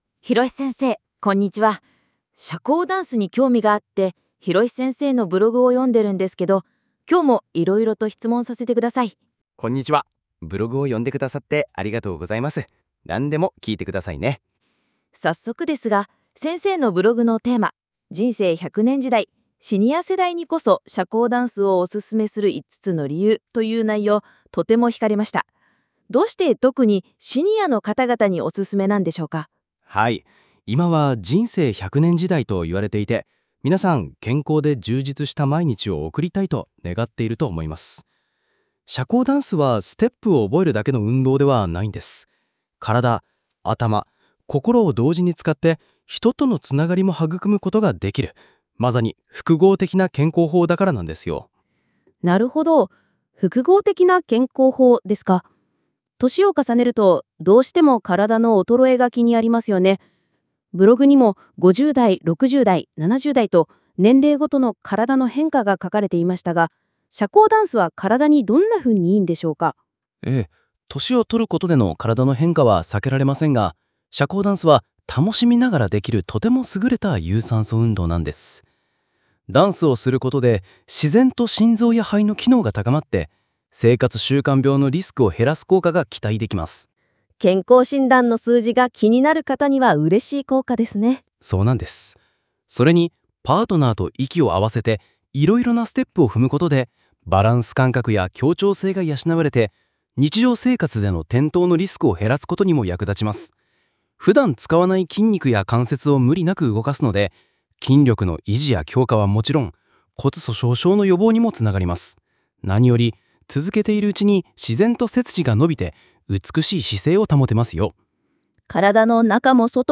今回のブログについても二人のAI談会話を音声で楽しめます。